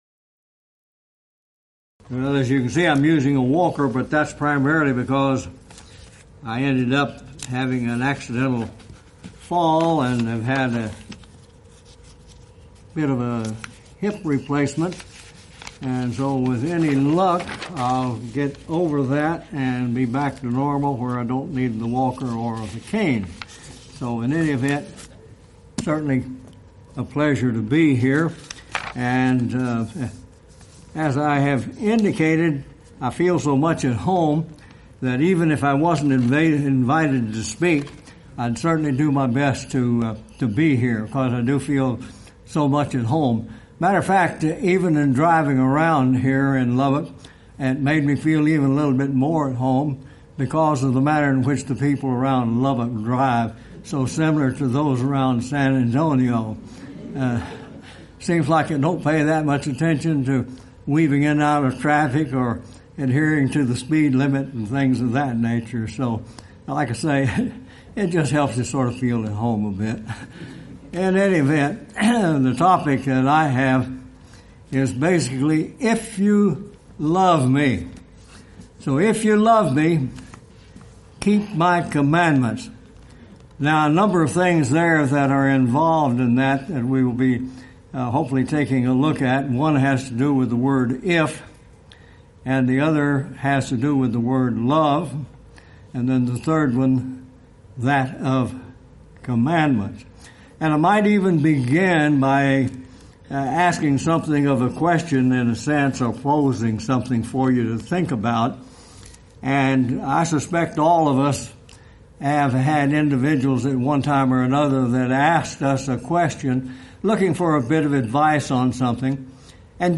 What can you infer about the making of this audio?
Event: 26th Annual Lubbock Lectures